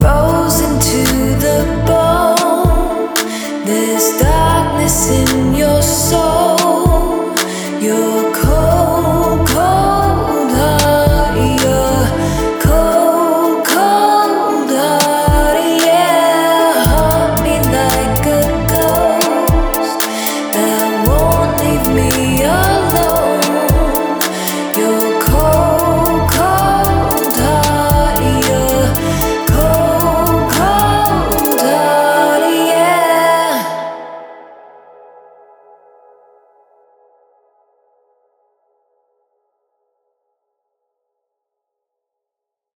So the differences you’ll hear between different mastering presets are also very small and often have more to do with width, depth, clarity, or punch rather than noticeable loudness.
Now, let’s check out the differences in the sound when I used two different presets on the demo track:
Punchy
Mastering-Presets-Demo-Punchy-mastering.mp3